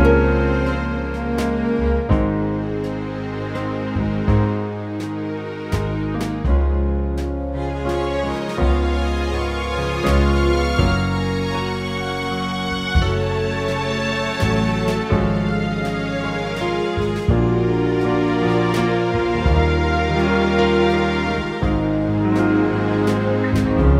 no Backing Vocals Oldies (Female) 3:11 Buy £1.50